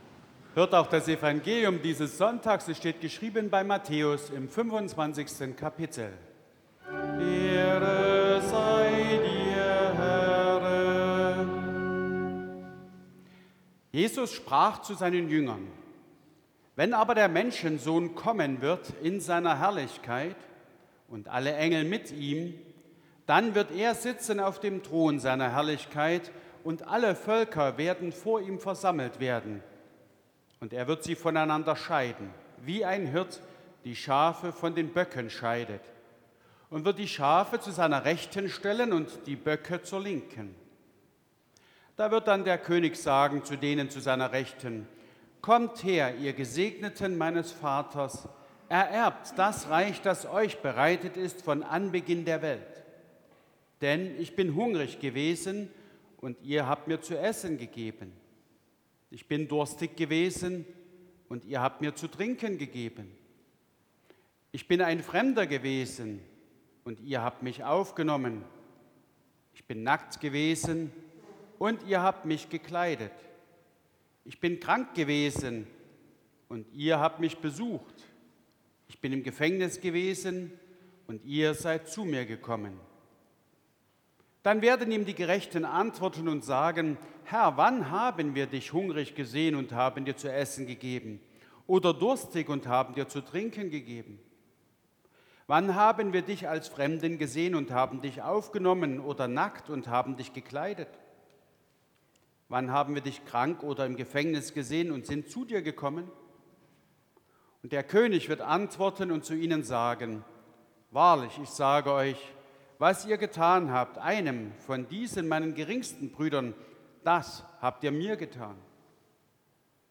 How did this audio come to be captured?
Audiomitschnitt unseres Gottesdienstes vom Vorletzten Sonntag im Kirchenjahr 2024